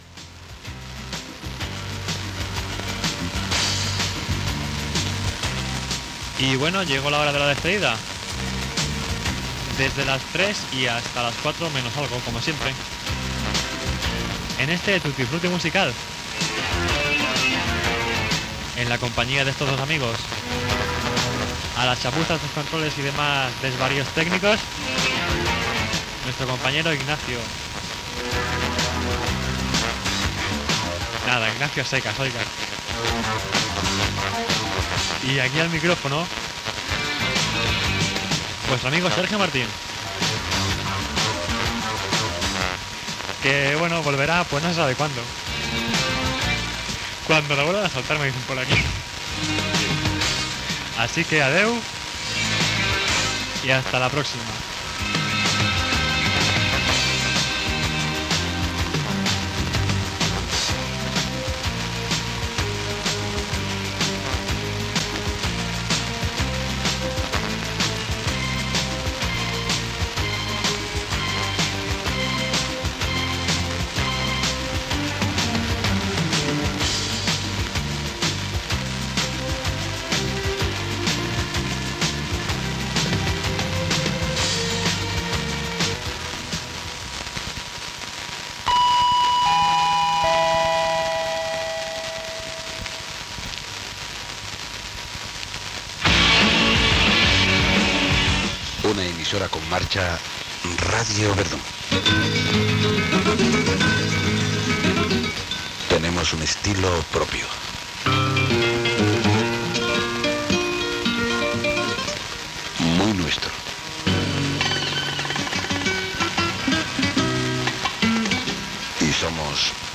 Comiat del programa, hora, flaix informatiu i inici de "Música de orquestas, sobremesa musical".
FM
Qualitat de l'àudio deficient.